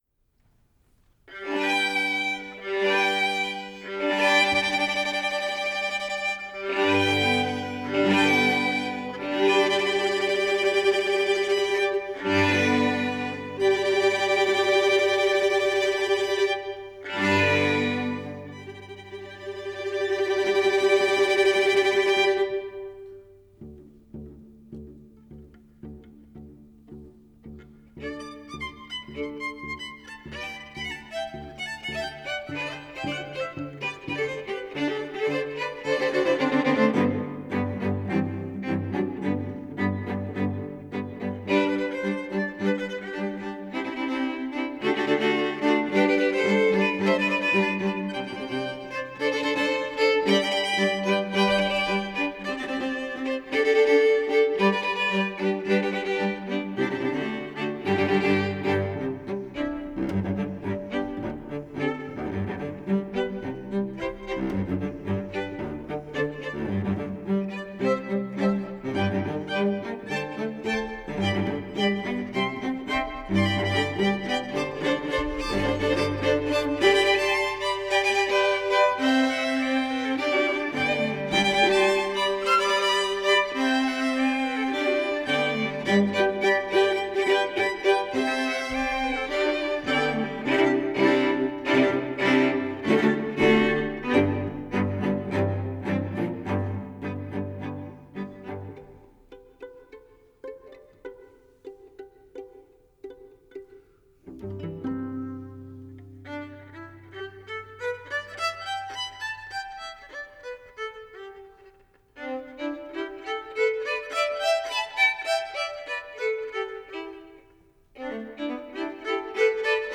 for String Quartet (2016)
Little dots fill the middle section.
The notes huddle together.
The notes then converge to a unison ending.